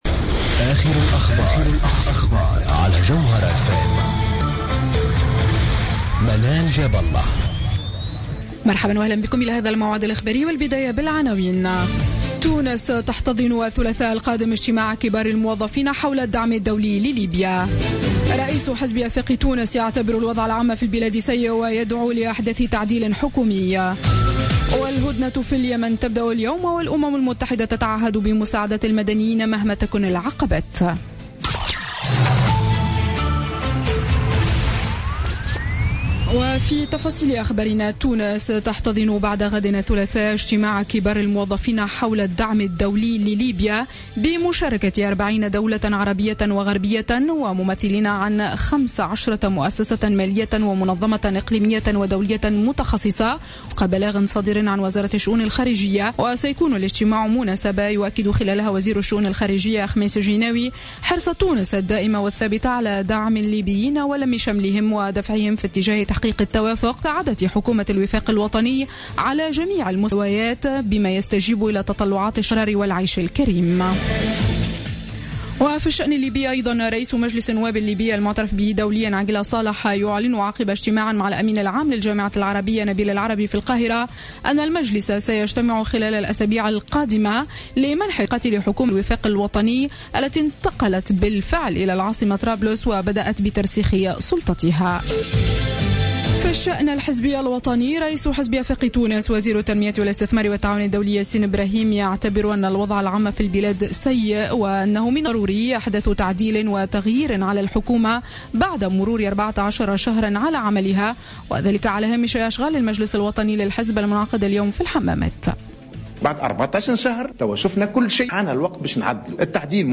نشرة أخبار السابعة مساء ليوم الأحد 10 أفريل 2016